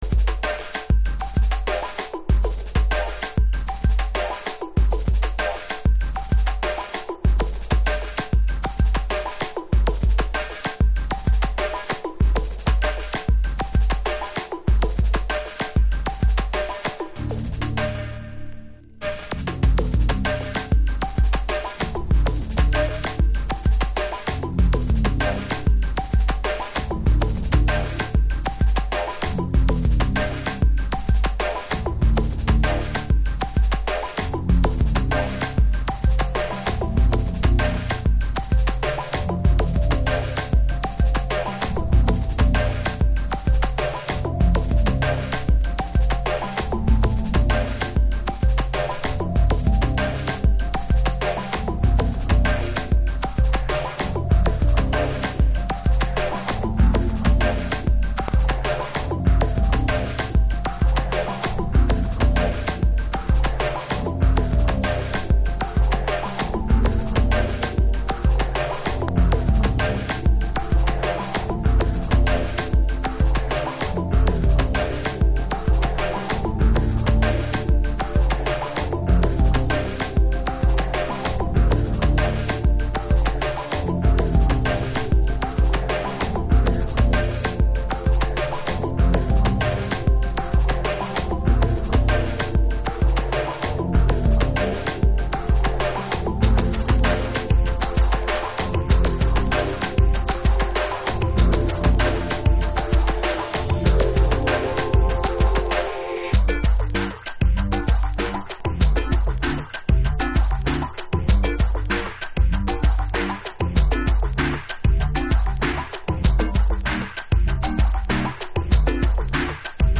Early House / 90's Techno
ピュアな鳴りとメロデーに痺れます。